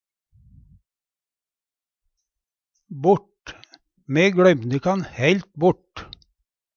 DIALEKTORD PÅ NORMERT NORSK bort bort Eksempel på bruk Me gløymde kan heilt bort.